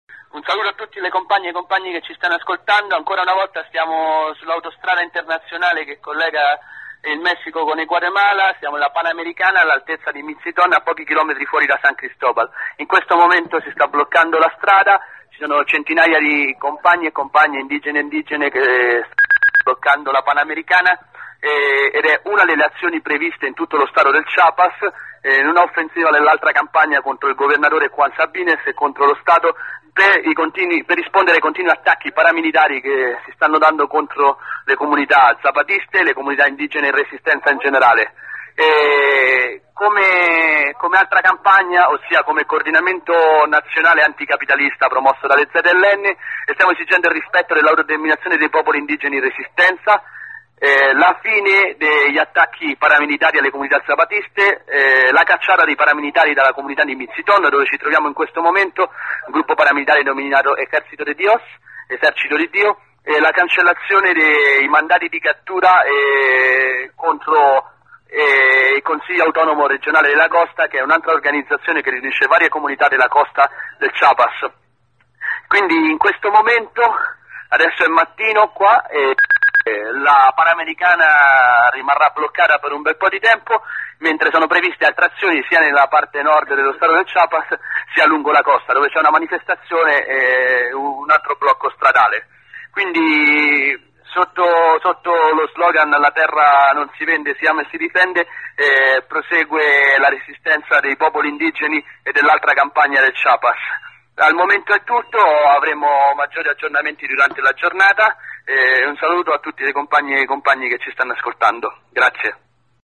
contributo audio dal Chiapas di un compagno del Nodo Solidale.